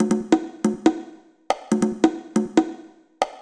140_conga_1.wav